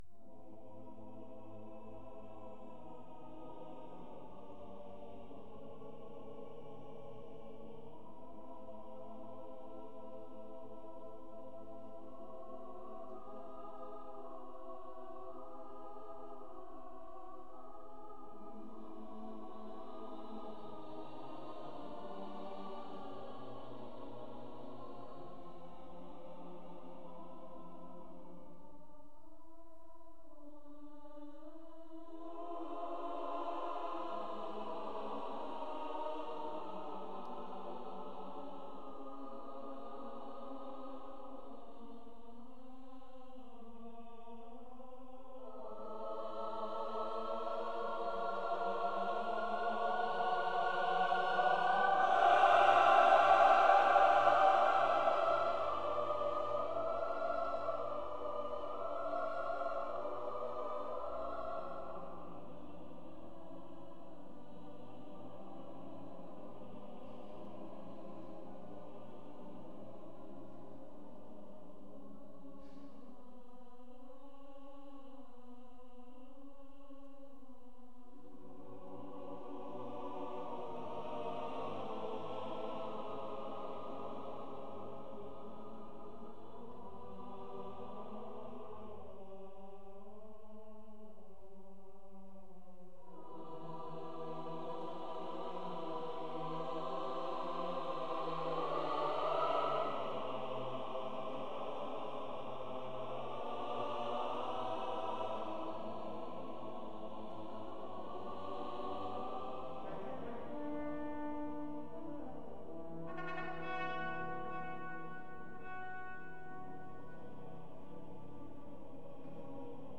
by Montreal Symphony Orchestra and Chorus; Charles Dutoit | Ravel: Daphnis et Chloé